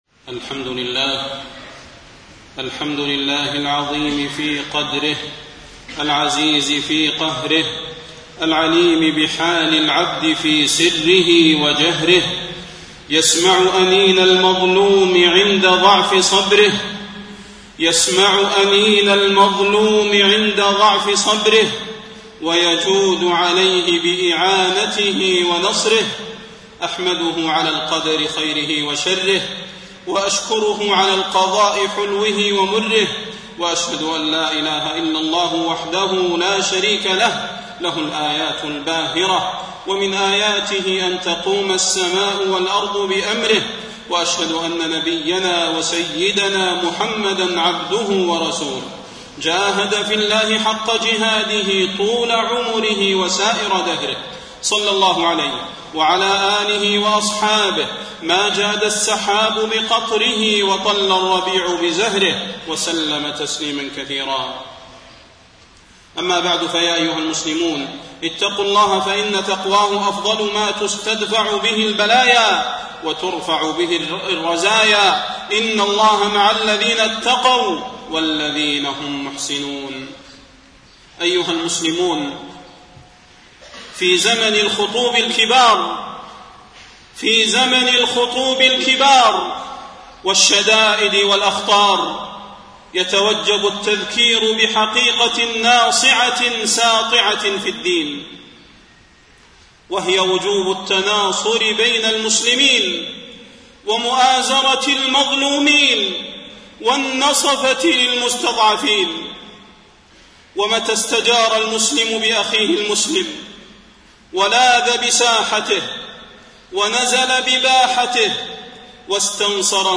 تاريخ النشر ٢٤ جمادى الآخرة ١٤٣٢ هـ المكان: المسجد النبوي الشيخ: فضيلة الشيخ د. صلاح بن محمد البدير فضيلة الشيخ د. صلاح بن محمد البدير وجوب التناصر بين المسلمين